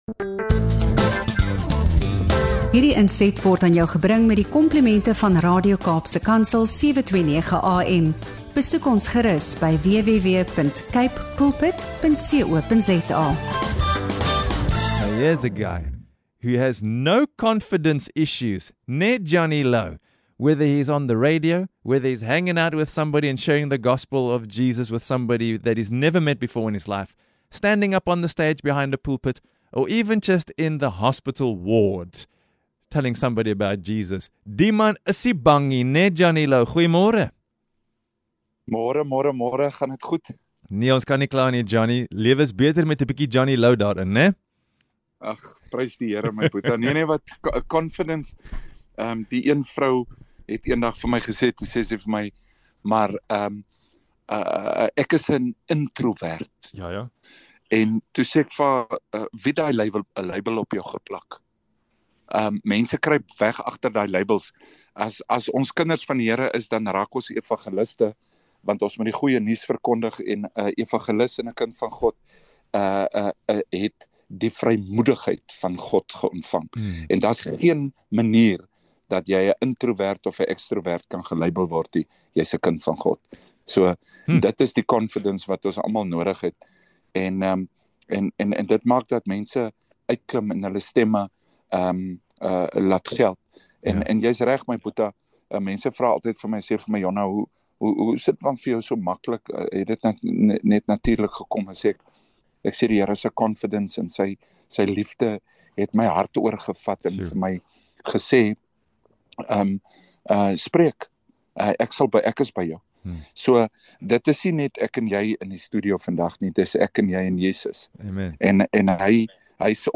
In hierdie opregte gesprek